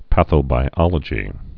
(păthō-bī-ŏlə-jē)